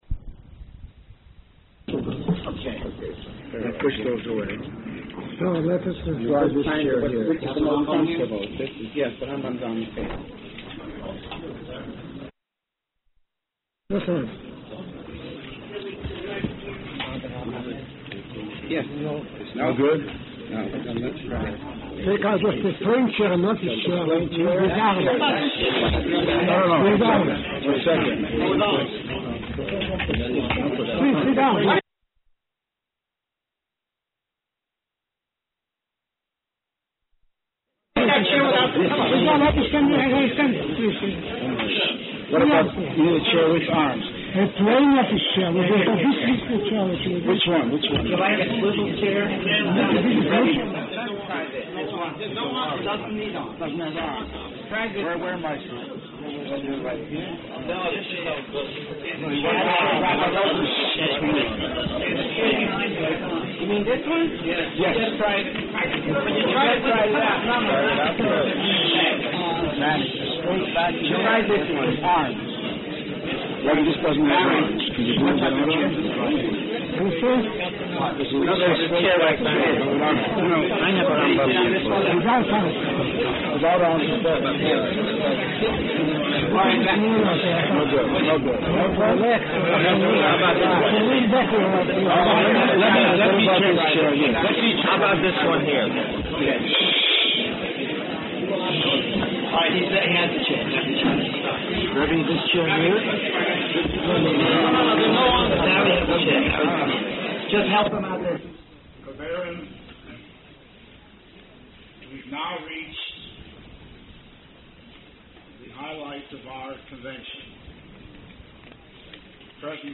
Behaaloscha Shlach - The Rav in his Own Voice - OU Torah